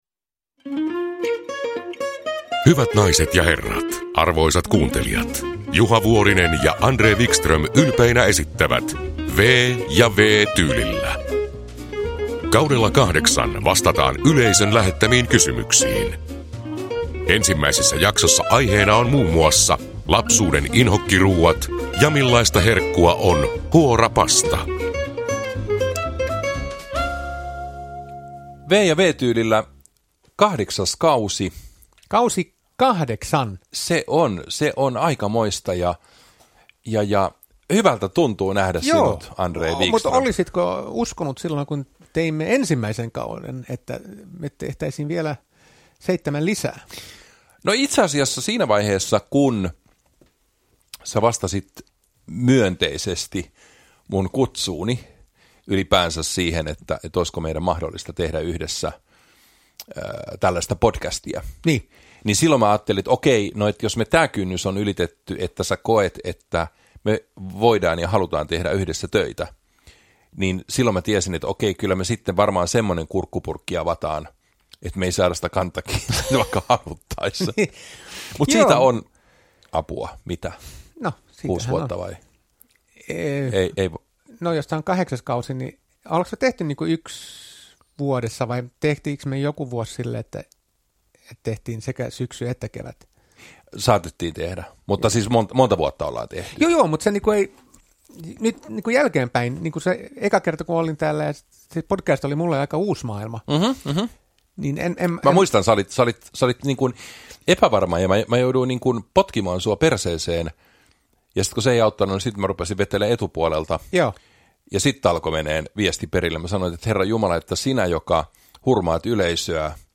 Uppläsare: Juha Vuorinen, André Wickström
• Ljudbok